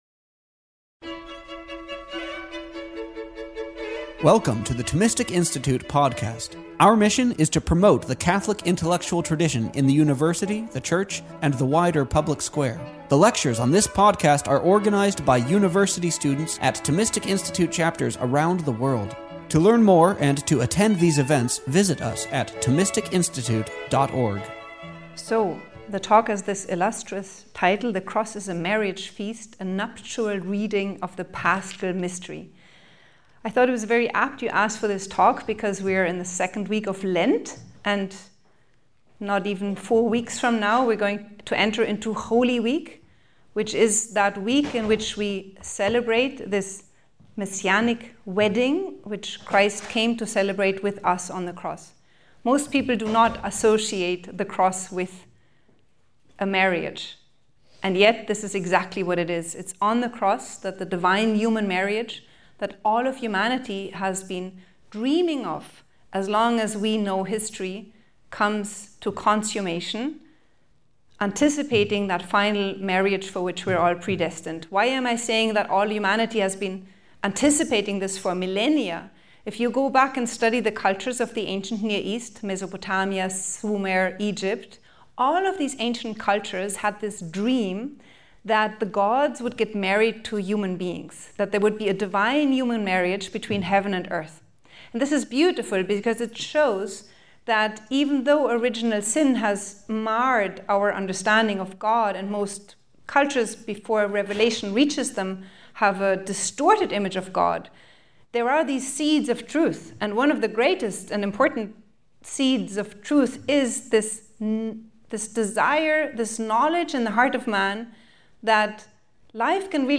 This lecture was given on November 6th, 2023, at The University of Texas at Austin.